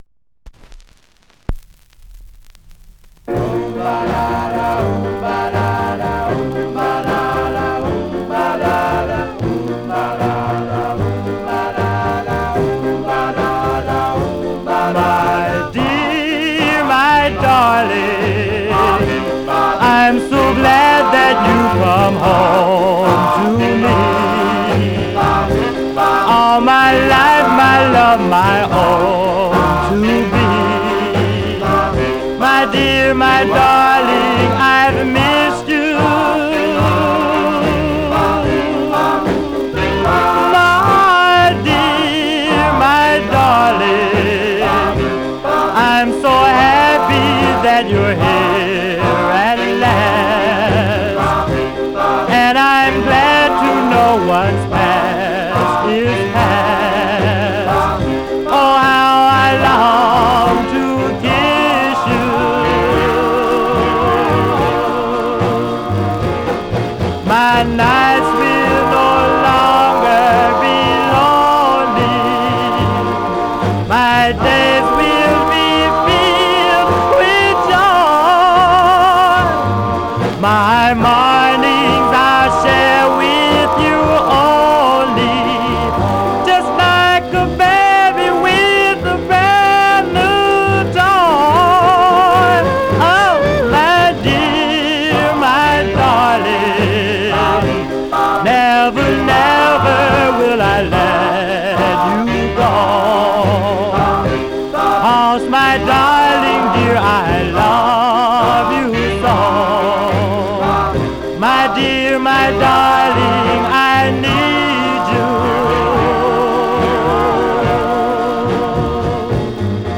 Mono
Male Black Group Condition